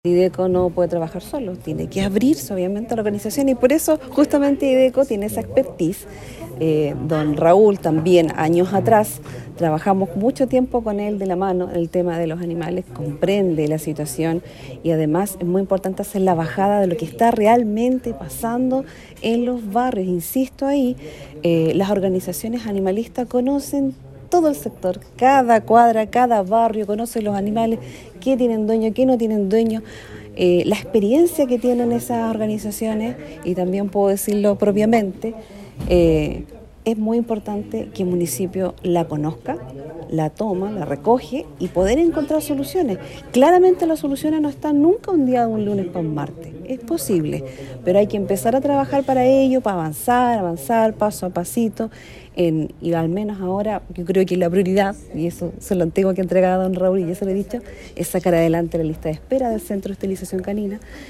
Un punto importante al que se refirió Canales fue la experiencia que tienen las organizaciones animalistas, pues ellas conocen en terreno la situación de los distintos sectores de Osorno.